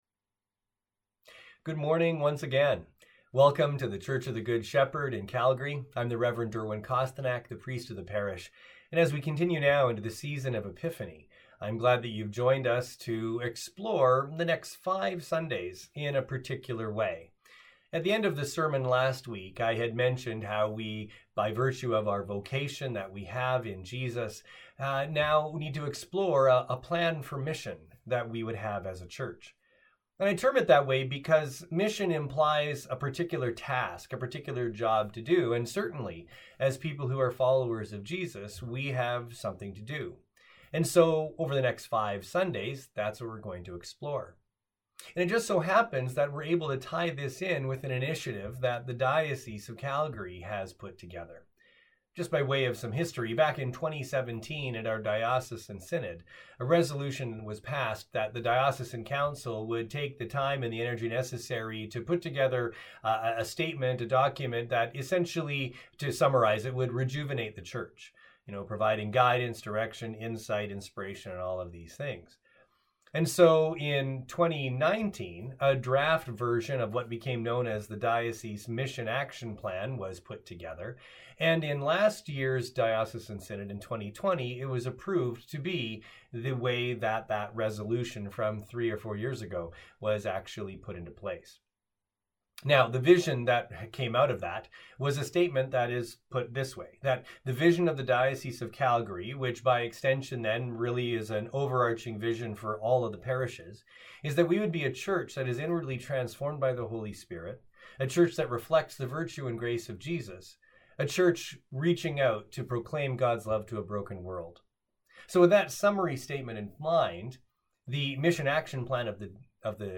Sermons | The Church of the Good Shepherd